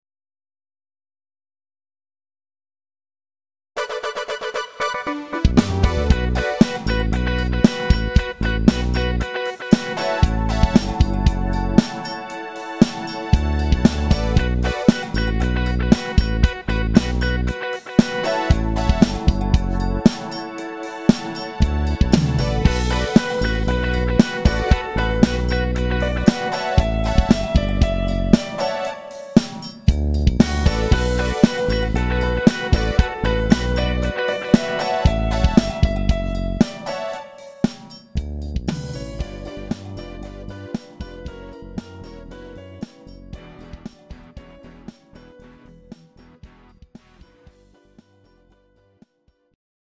Pop Dance Music